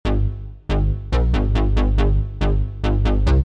经典技术型低音提琴140
描述：一个经典的技术派低音循环
标签： 140 bpm Techno Loops Bass Loops 590.68 KB wav Key : Unknown
声道立体声